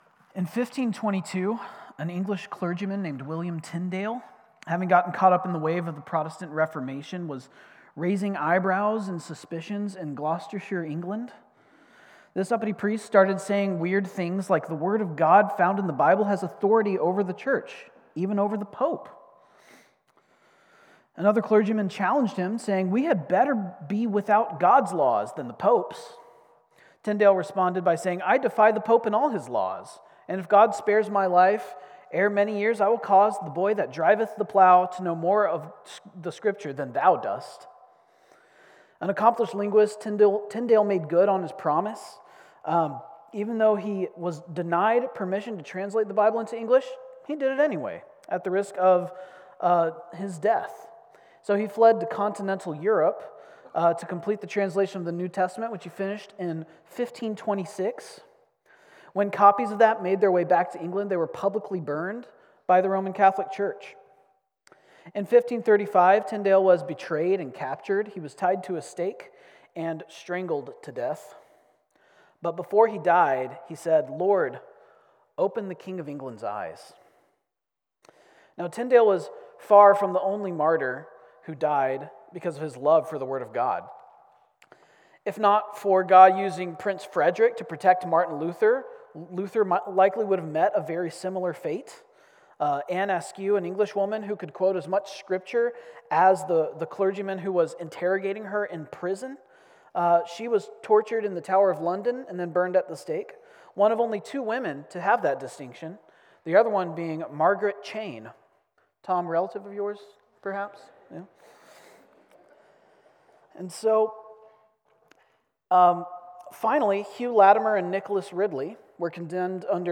CCBC Sermons Psalm 119:1-8 Apr 06 2025 | 00:26:25 1x 00:00 / 00:26:25 Subscribe Share Apple Podcasts Spotify Overcast RSS Feed Share Link Embed